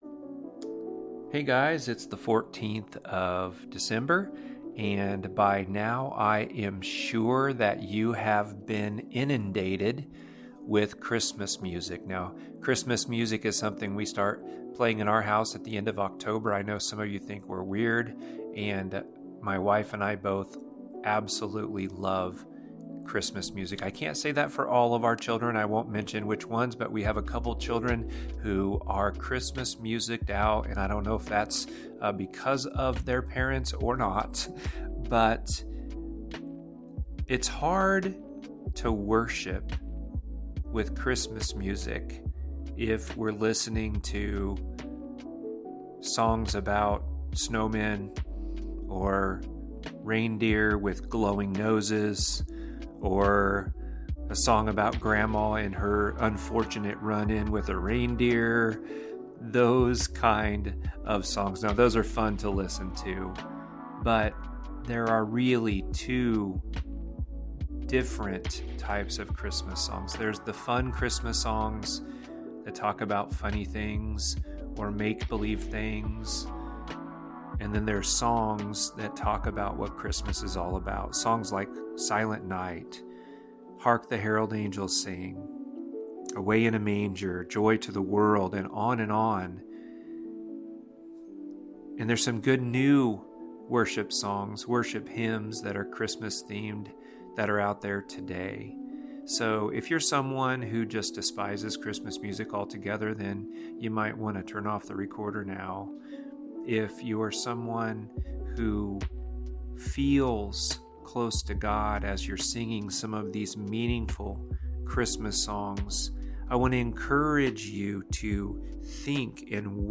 Pray Today | December 14 (Christmas Music)